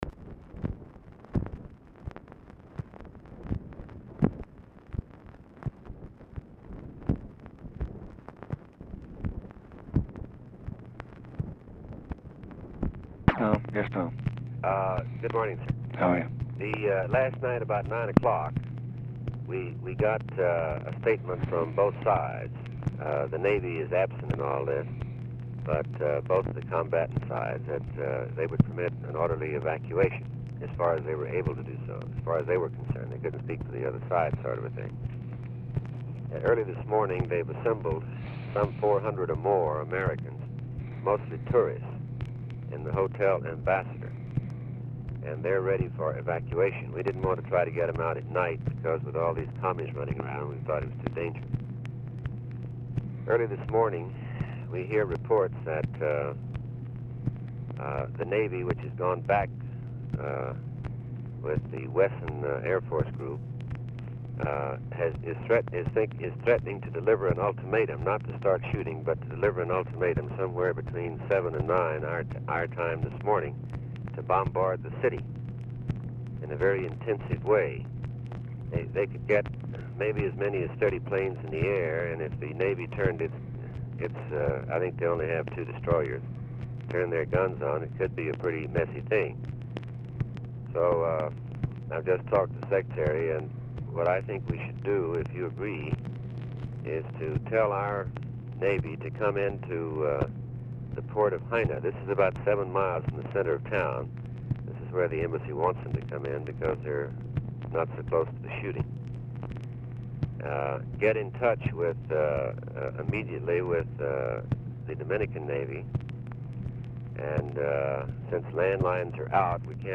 Telephone conversation # 7367, sound recording, LBJ and THOMAS MANN, 4/27/1965, 7:17AM | Discover LBJ